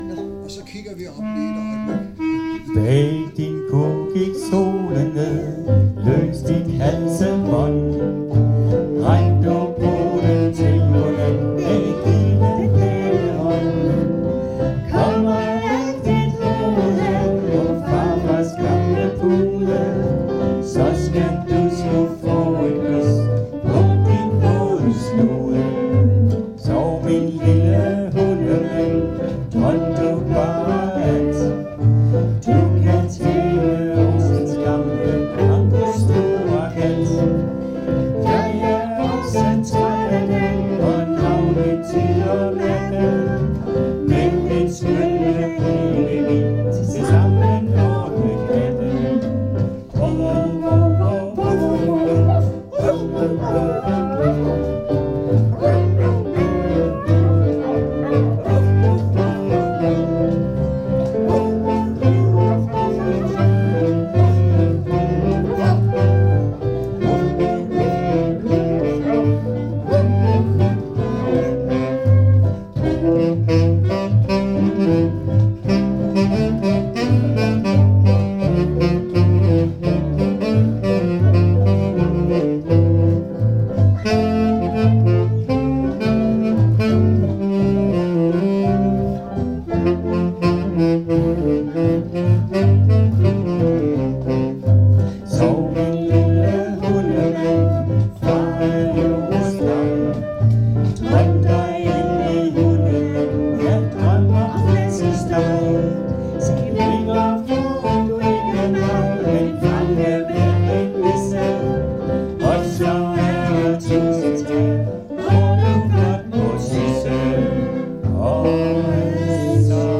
Øveaften 23. oktober 2024: